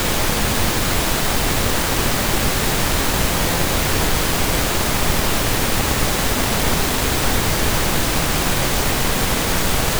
They start out simple, with a decent signal strength but they get progressively harder to make out as the signal becomes weaker and embedded deeper into the noise floor.